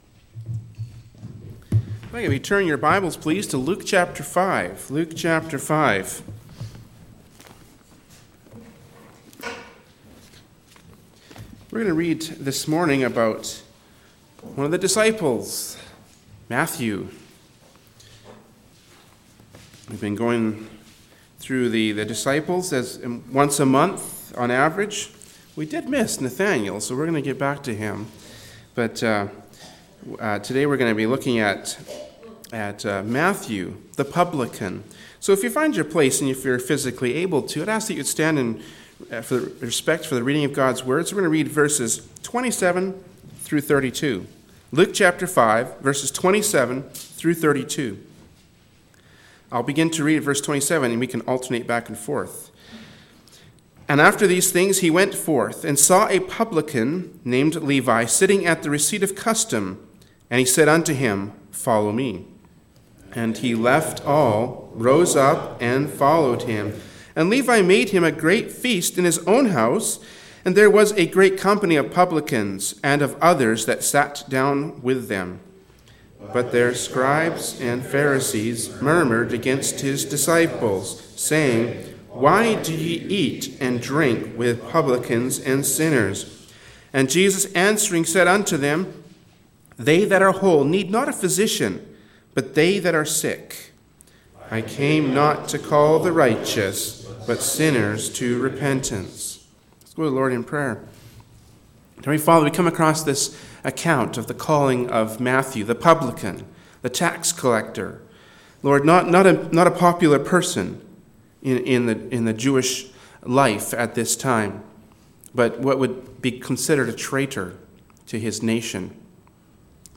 “Luke 5:27-32” from Sunday Morning Worship Service by Berean Baptist Church.
Luke 5:27-32 Service Type: Sunday Morning Worship Service “Luke 5:27-32” from Sunday Morning Worship Service by Berean Baptist Church.